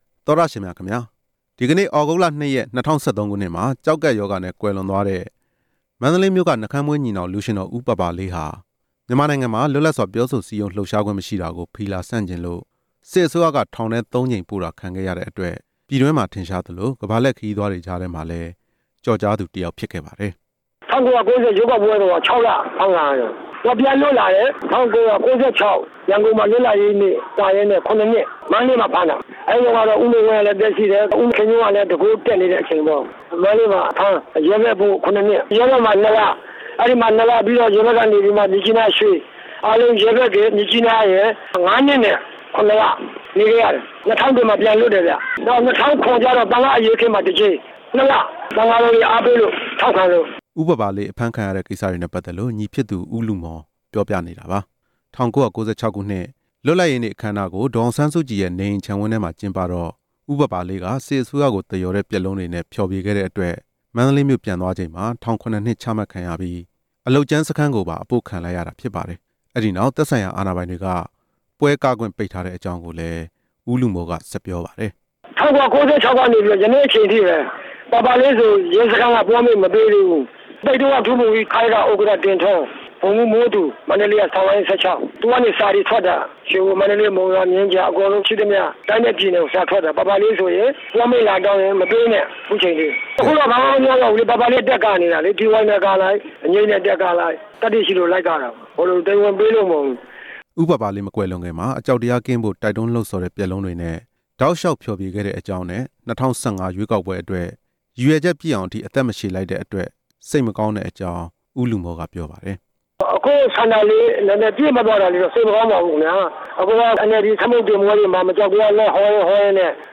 လူရွှင်တော် ဦးပါပါလေးအကြောင်း တင်ပြချက်